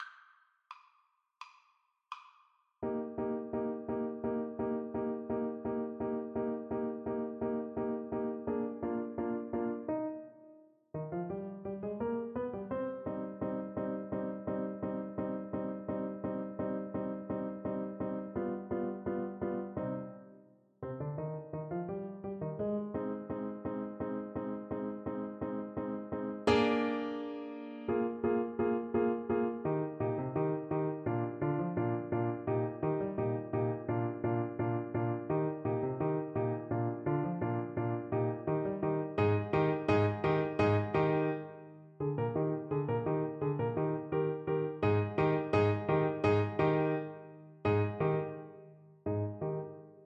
Clarinet
Arrangement for Clarinet and Piano
Eb major (Sounding Pitch) F major (Clarinet in Bb) (View more Eb major Music for Clarinet )
= 85 Allegro scherzando (View more music marked Allegro)
4/4 (View more 4/4 Music)
Classical (View more Classical Clarinet Music)